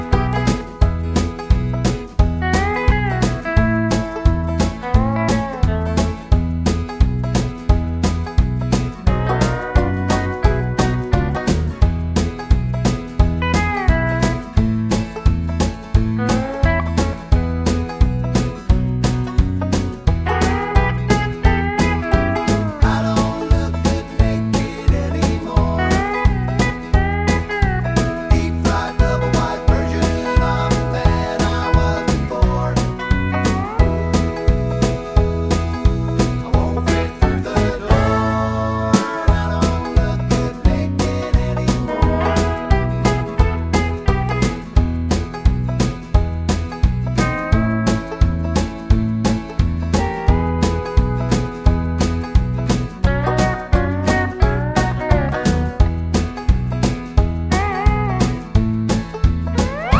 no Backing Vocals Comedy/Novelty 2:50 Buy £1.50